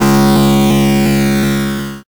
resonate.ogg